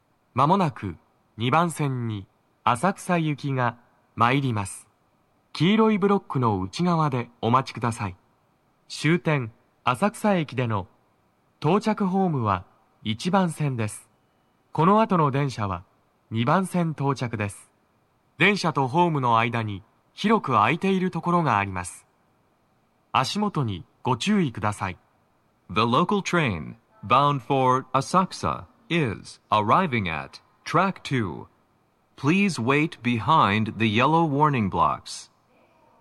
鳴動は、やや遅めです。
2番線 浅草方面 接近放送 【男声
接近放送1